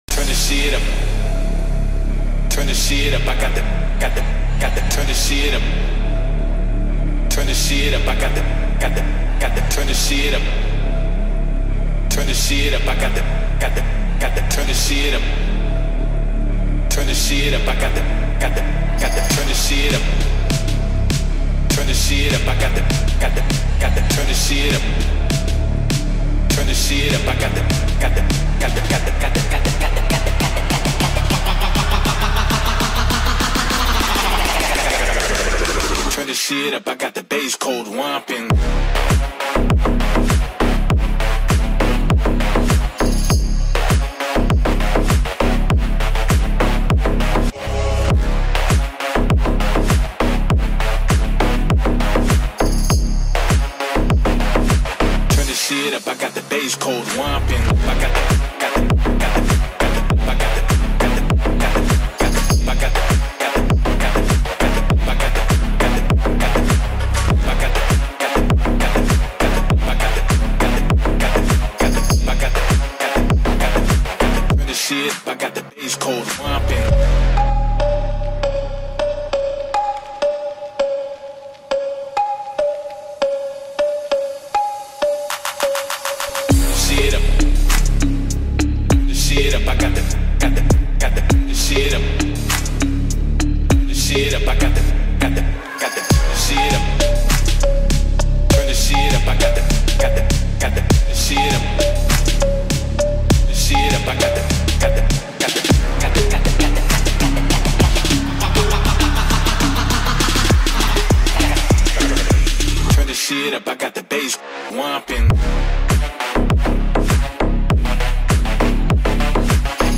это динамичная трек в жанре хип-хоп